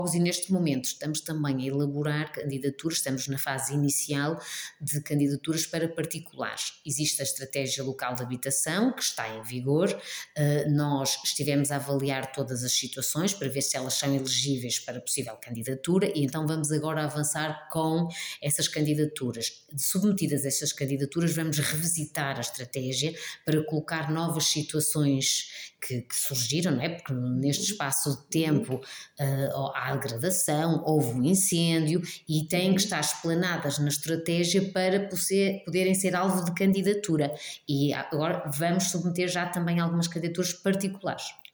Neste momento, a autarquia de Macedo de Cavaleiros está a executar uma estratégia local de habitação, como adianta a Vereadora: